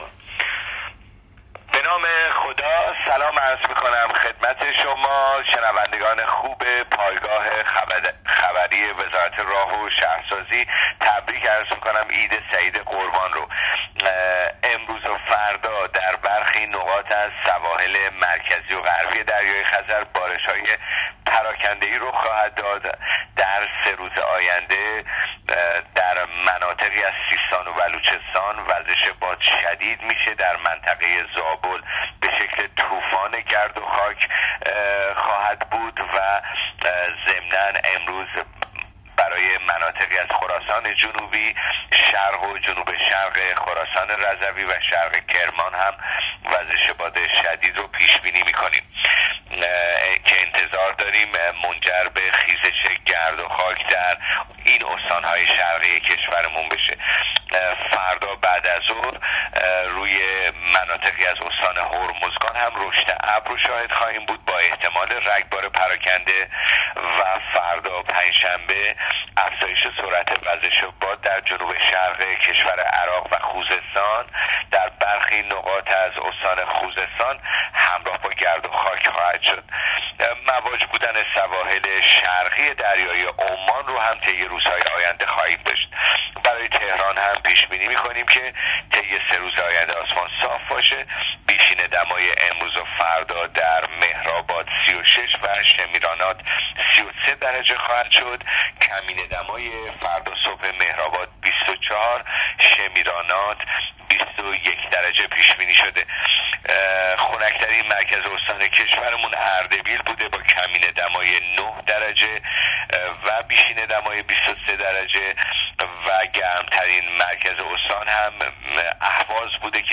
کارشناس سازمان هواشناسی در گفتگو با راديو اينترنتی پايگاه خبری آخرين وضعيت هوا را تشریح کرد.